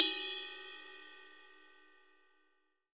RIDE 2.wav